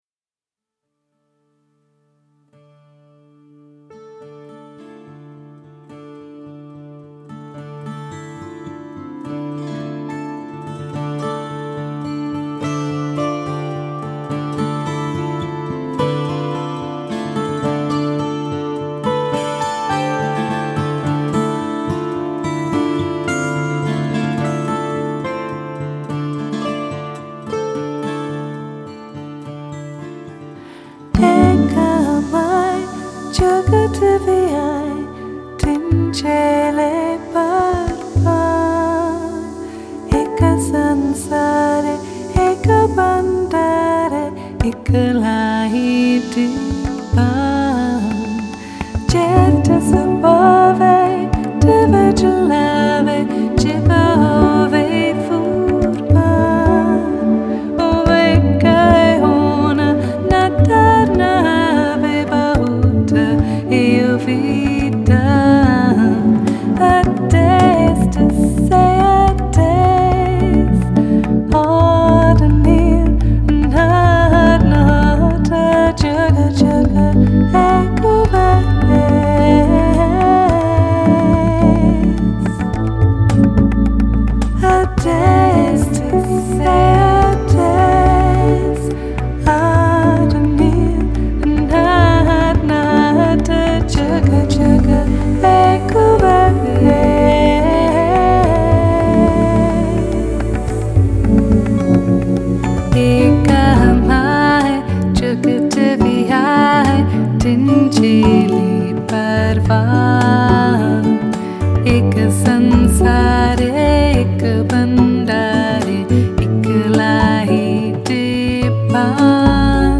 Genre: Mantra.